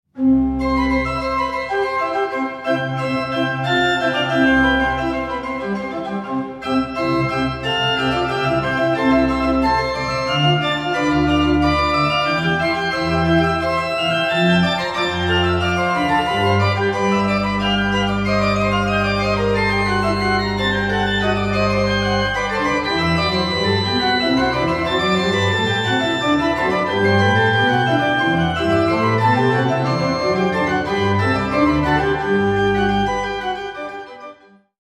Garnisons Kirke, Copenhagen